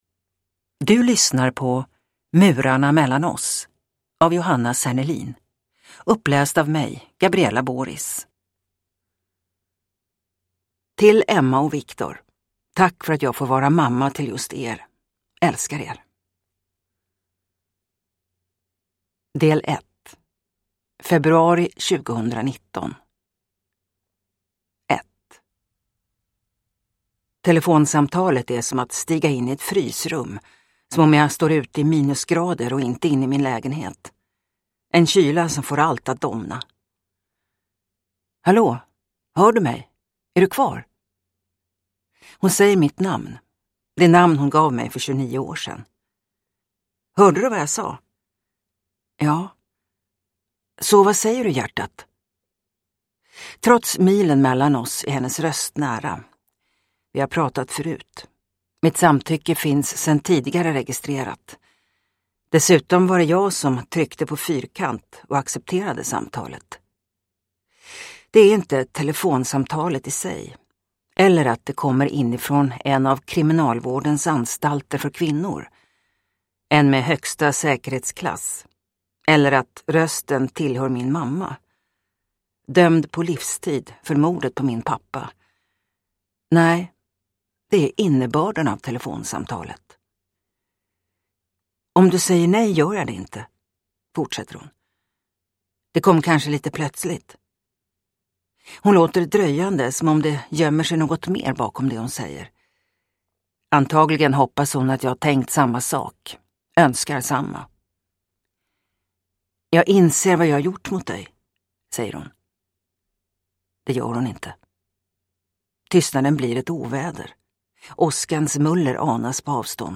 Murarna mellan oss – Ljudbok